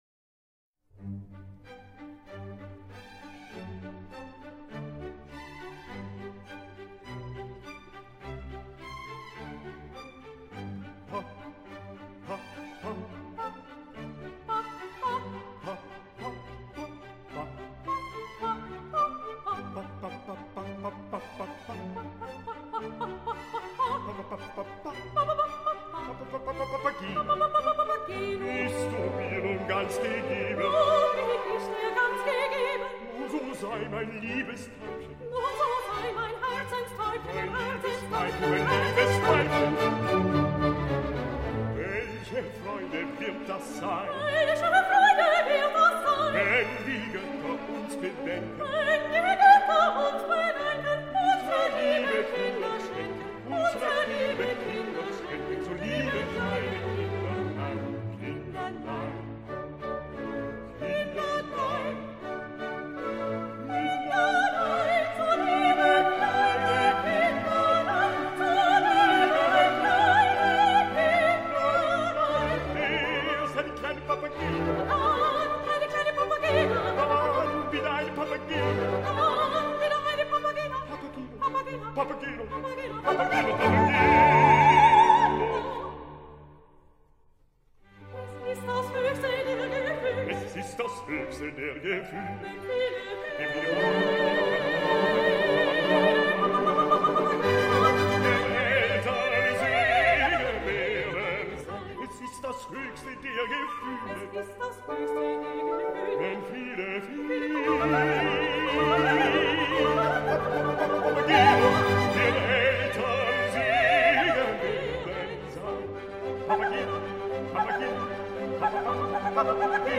Ópera